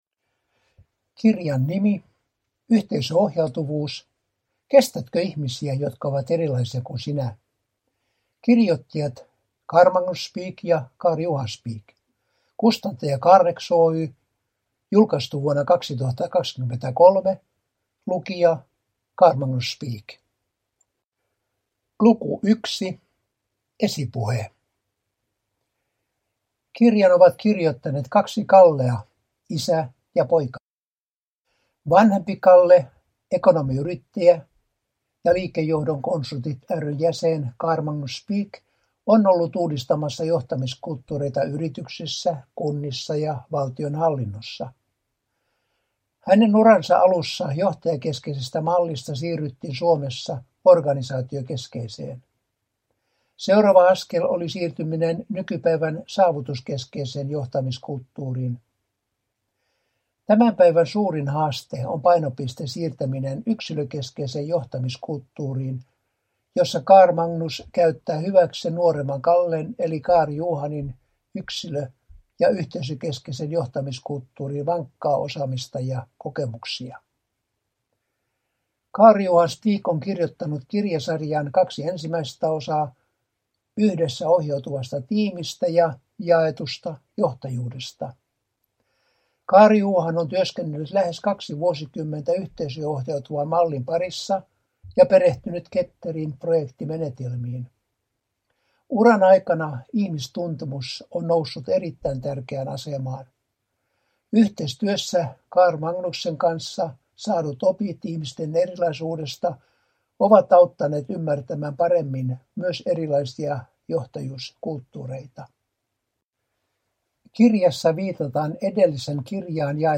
Yhteisöohjautuvuus : Kestätkö ihmisiä, jotka ovat erilaisia kuin sinä – Ljudbok